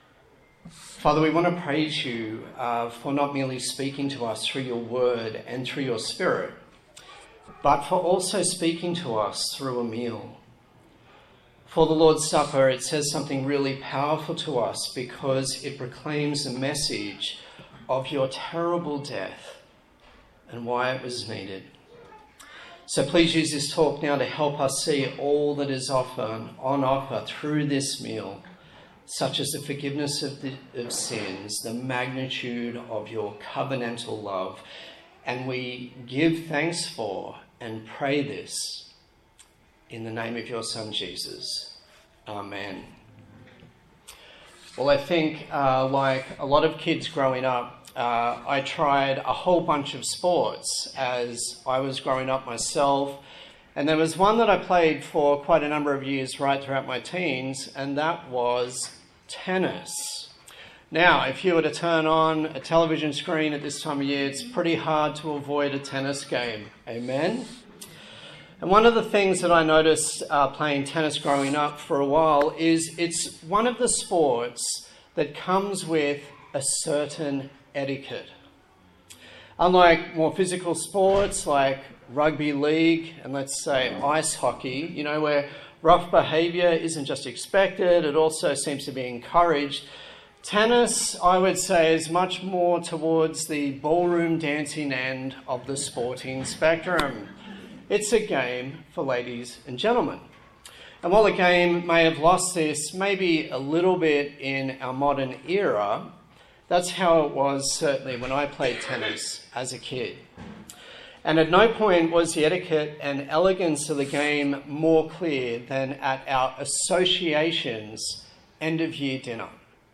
Passage: 1 Corinthians 11:17-34 Service Type: Sunday Morning A sermon